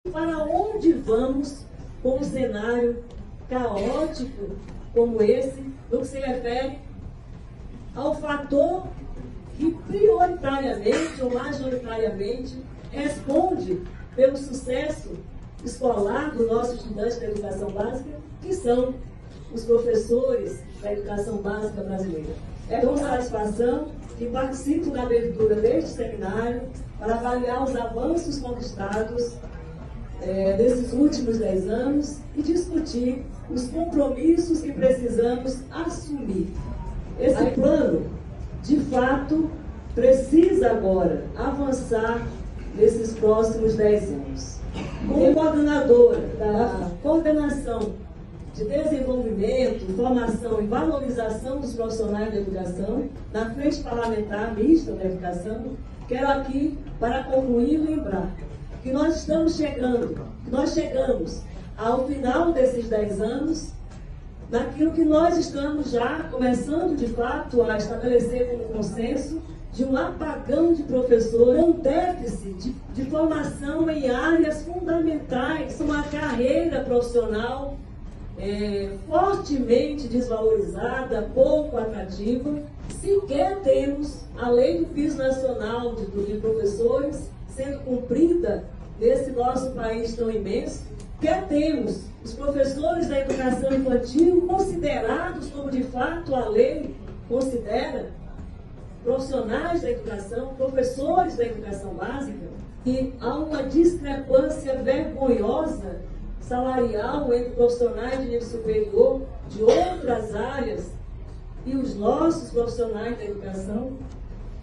A abertura do evento, que se estende durante toda a quarta-feira, 26, ocorreu no Auditório Freitas Nobre, na Câmara dos Deputados, e contou com a presença de profissionais e defensores da educação.
Fala da Deputada Federal Socorro Neri;